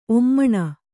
♪ ommaṇa